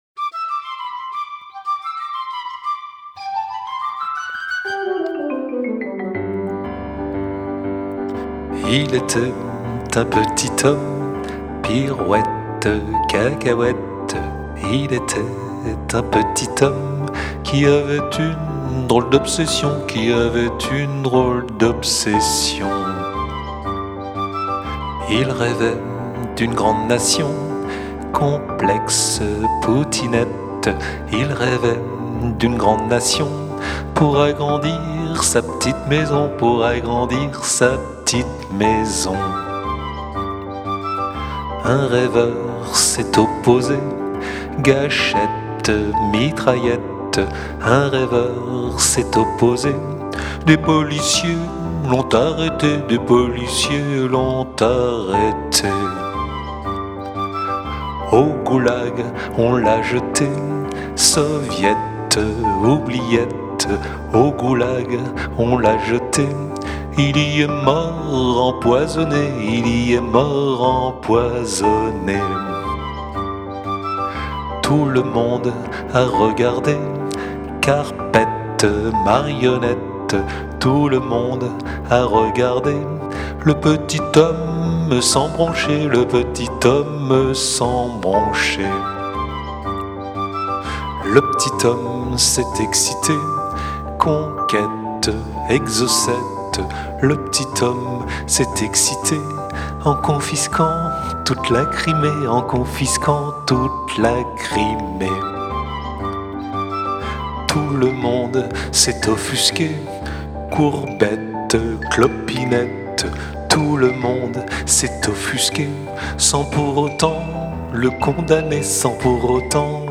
maquette dans son jus
Contrebasse et basse
Batterie
Guitares